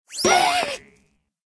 avatar_emotion_surprise.ogg